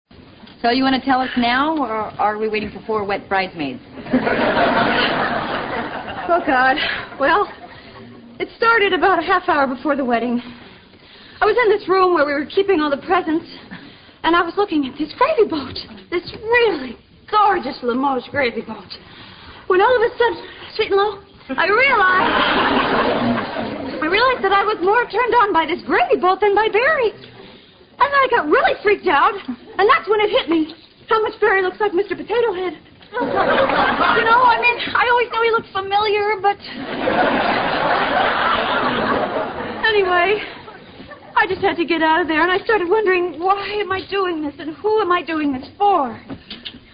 Friends, 中文译名为《六人行》或《老友记》是美国正在热播的一个sitcom (即situation comedy) 也就是我们所说的情景喜剧。